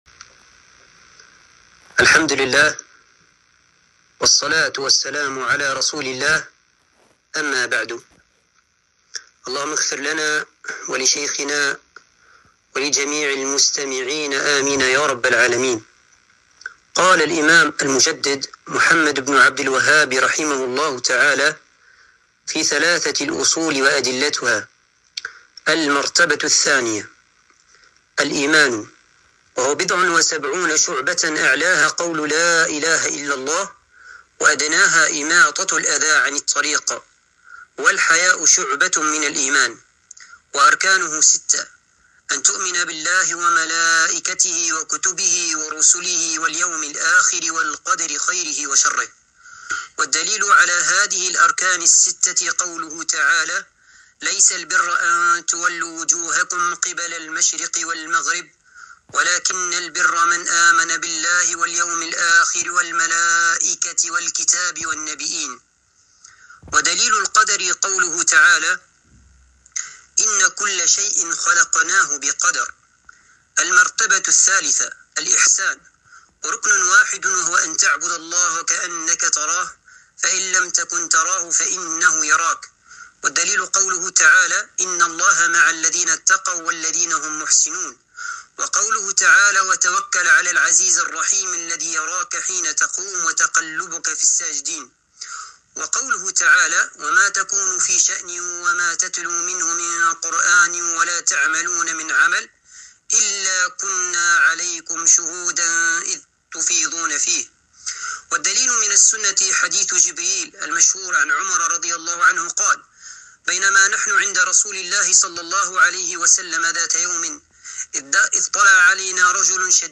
الدرس الخامس - شرح ثلاثة الأصول